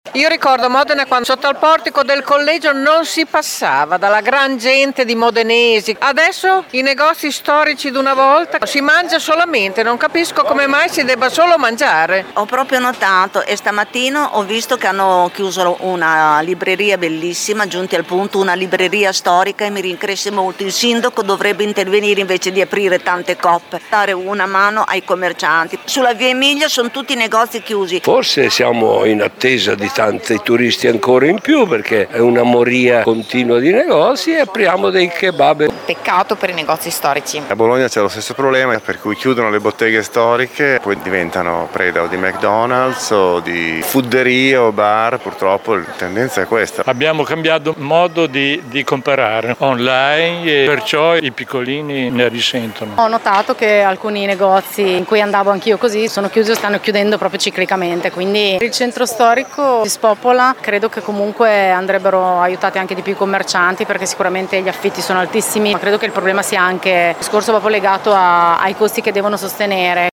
Cosa ne pensano i modenesi?
VOX-NEGOZI.mp3